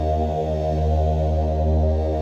WAIL PAD 1.wav